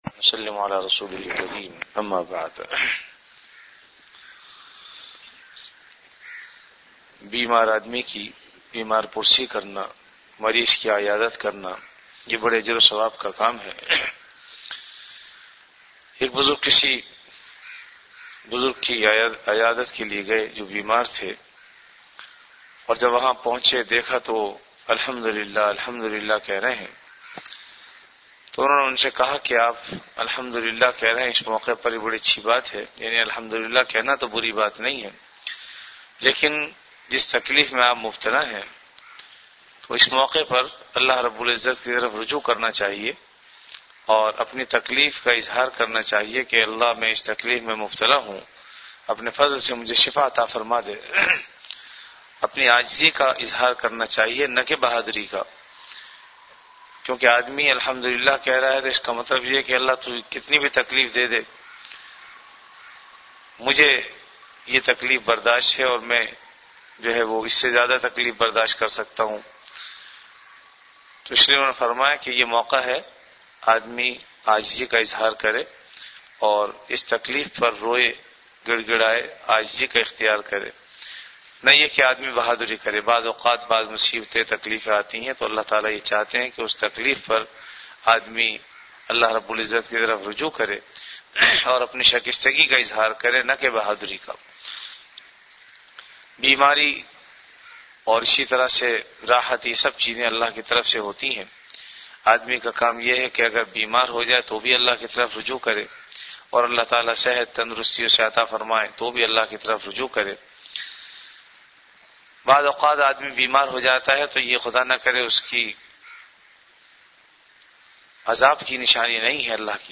Delivered at Jamia Masjid Bait-ul-Mukkaram, Karachi.